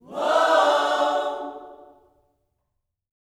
WHOA-OHS 1.wav